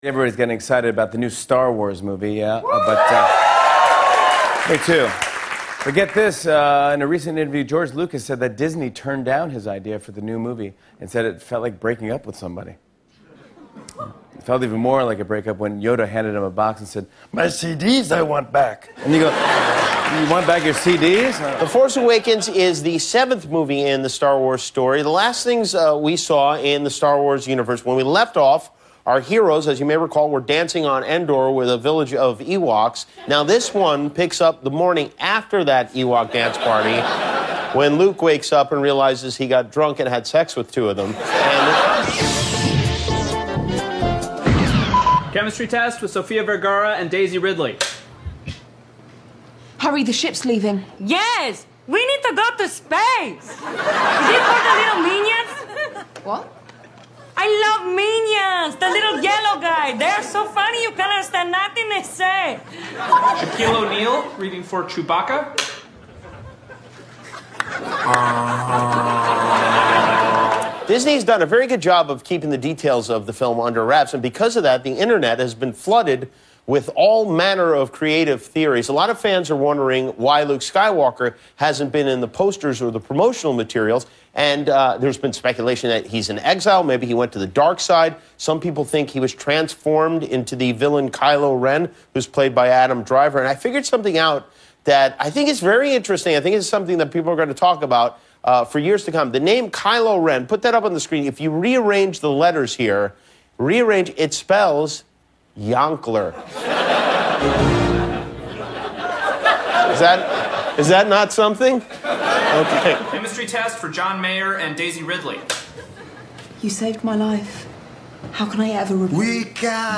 The late-night comics take a look at the magic behind "The Force Awakens."